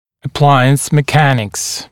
[ə’plaɪəns mɪ’kænɪks][э’плайэнс ми’кэникс]механические принципы работы аппаратуры